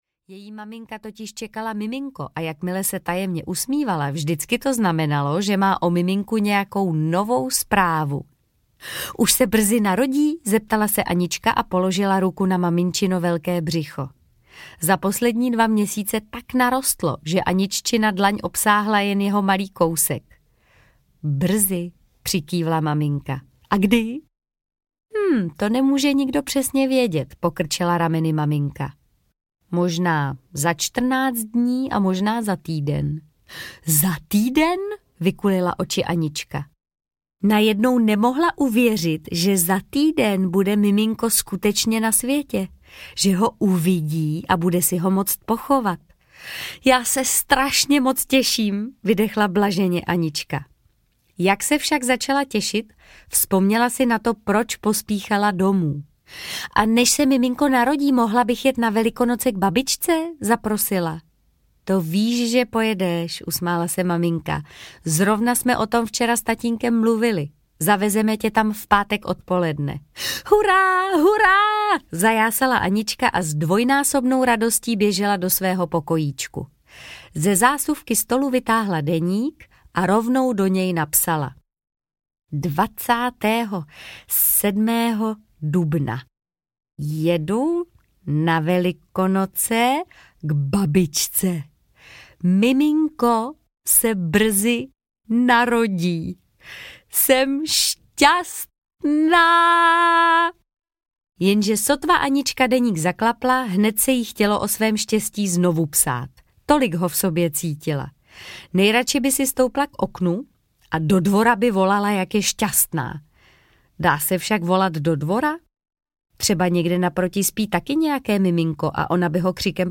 Anička a Velikonoce audiokniha
Ukázka z knihy
• InterpretMartha Issová